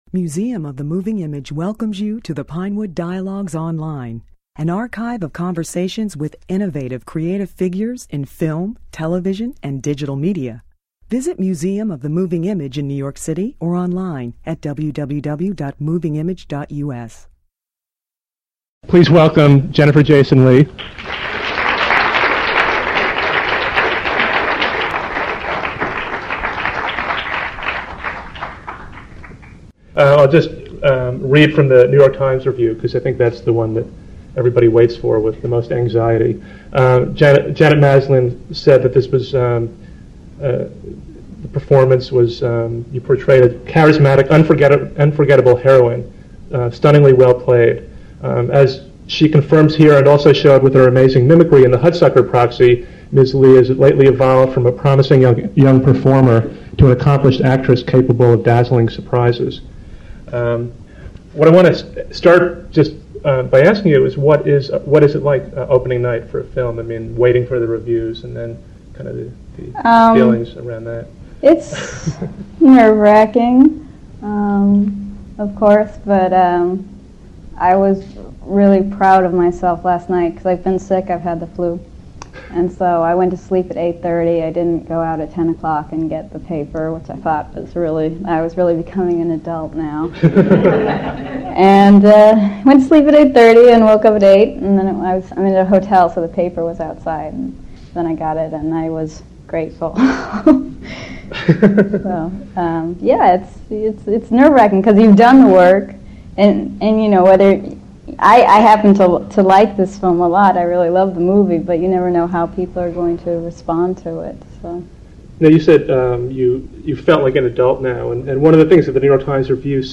She spoke at the Museum on the day she received rave reviews for her dazzling portrayal of Dorothy Parker in Mrs. Parker and the Vicious Circle .